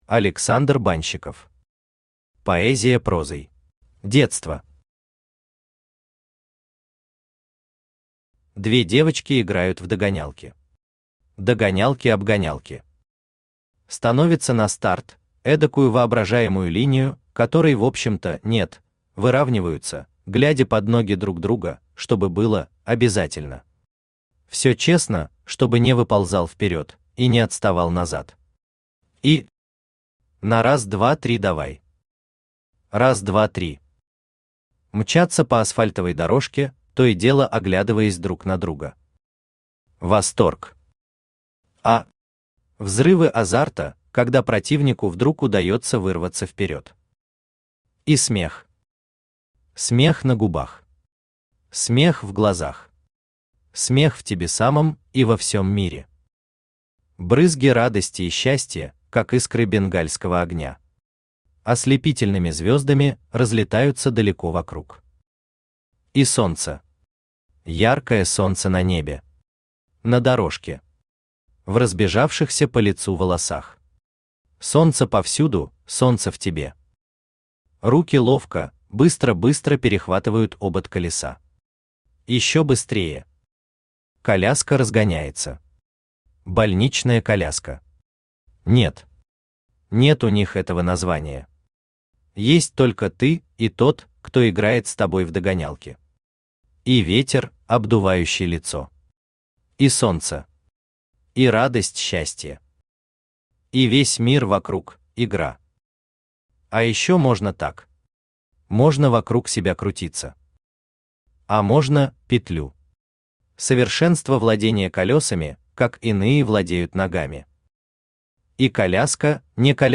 Аудиокнига Поэзия прозой | Библиотека аудиокниг
Aудиокнига Поэзия прозой Автор Александр Валентинович Банщиков Читает аудиокнигу Авточтец ЛитРес.